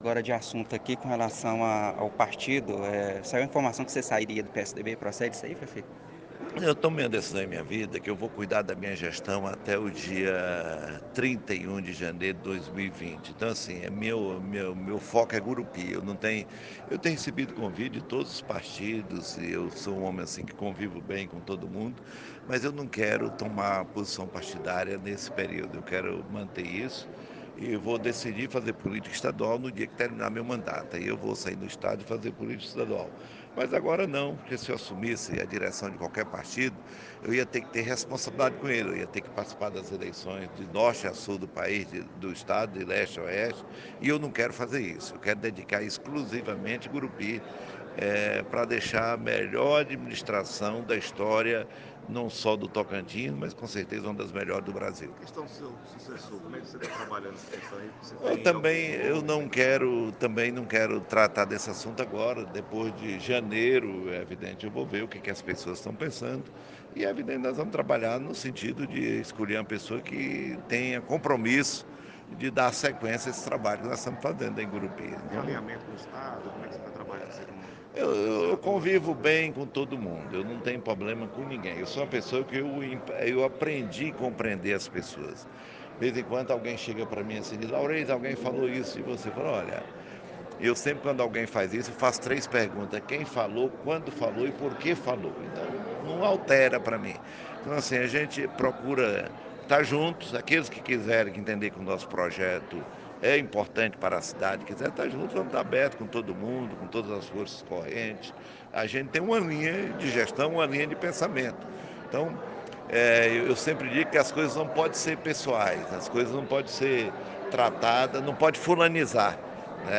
No lançamento da programação do aniversário de Gurupi o prefeito, Laurez Moreira (PSDB), foi questionado pelo Portal Atitude sobre a sucessão municipal. O prefeito disse que o seu candidato em 2020 tem que pensar no coletivo e sem “fulanizar”. Laurez disse ainda que tem recebido propostas de vários partidos para se filiar, mas que seu foco está apenas em administrar Gurupi.